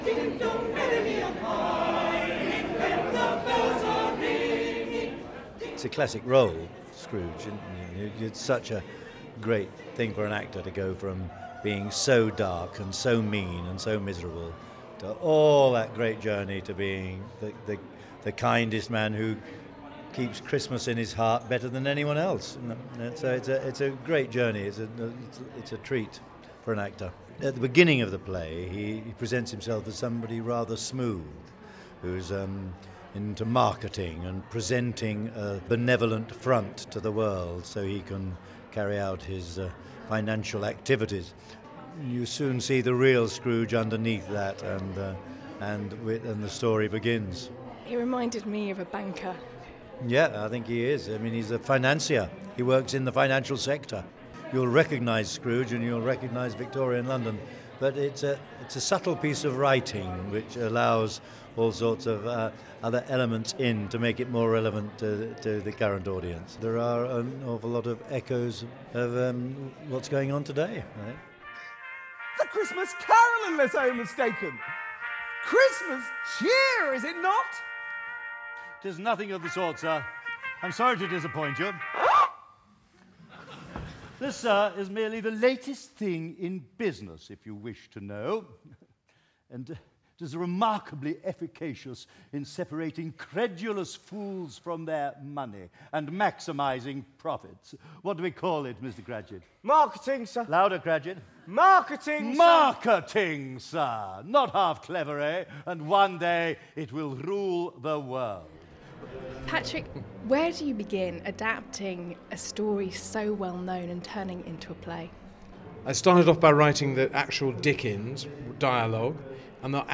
Jim Broadbent talks to me on his return to the stage after 10 years away. He was coaxed back to play the anti-hero in Patrick Barlow's version of Dickens' festive fable A Christmas Carol.